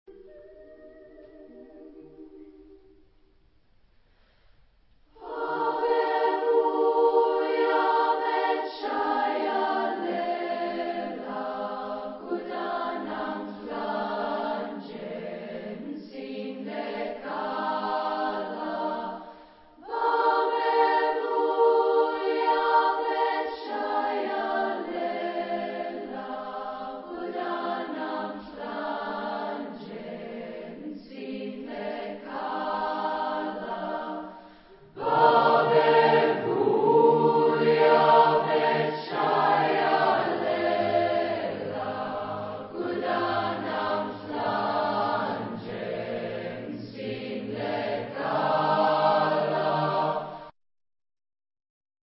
Genre-Style-Forme : Traditionnel
Caractère de la pièce : affectueux
Instrumentation : Batterie  (1 partie(s) instrumentale(s))
Instruments : Tambours (2)
Tonalité : sol majeur ; mi bémol majeur